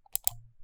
Original creative-commons licensed sounds for DJ's and music producers, recorded with high quality studio microphones.
mouse clicks.wav
Mouse clicks recorded with a Sterling ST66 mic, nearby.